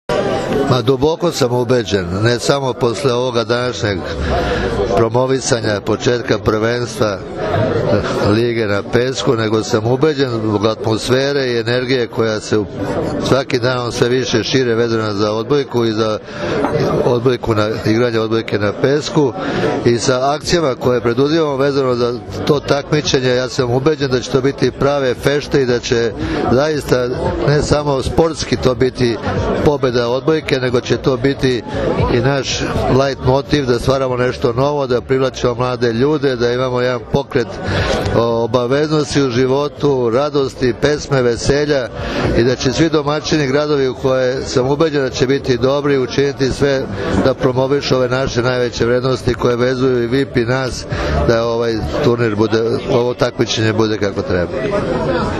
U beogradskom klubu “Lemon Čili” danas je svečano najavljen 9. „Vip Beach Masters 2016. – Prvenstvo Srbije u odbojci na pesku”, odnosno 5. „Vip Beach Volley Liga“.
IZJAVA